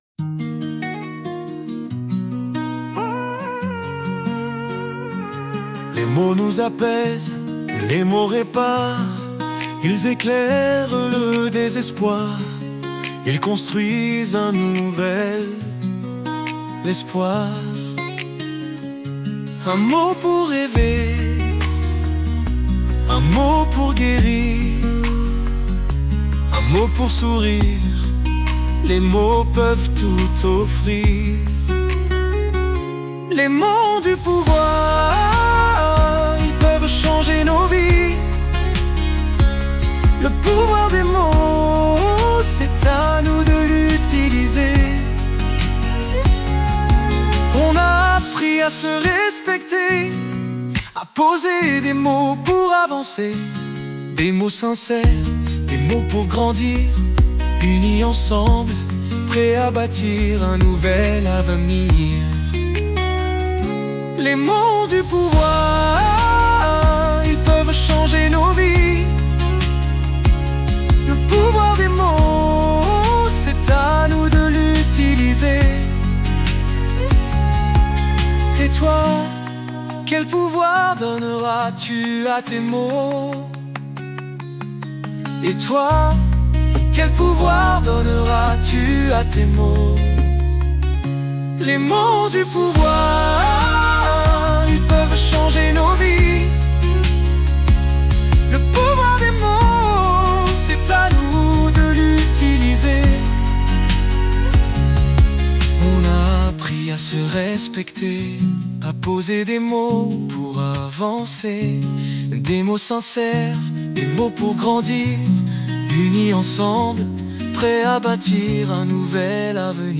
10-chant-final.mp3